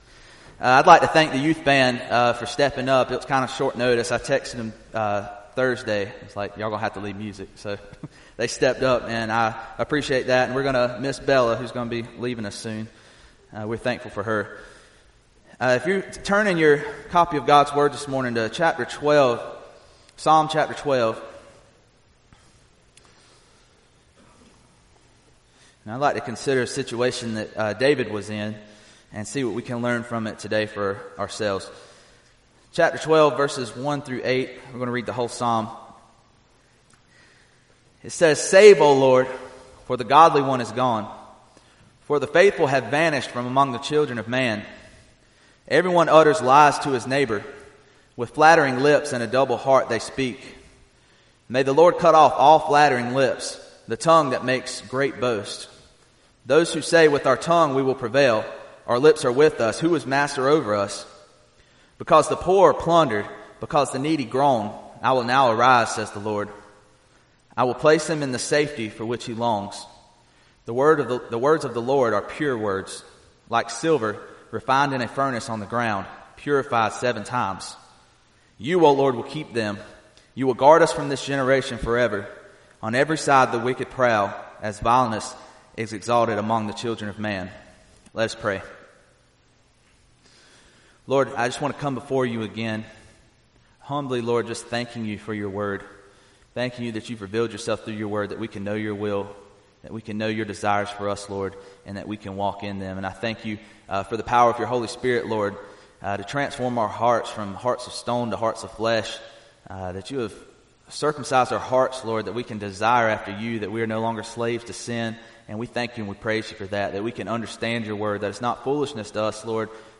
Psalms | Sermon Series | Dallasburg Baptist Church
February 9, 2020 (Sunday Morning Service)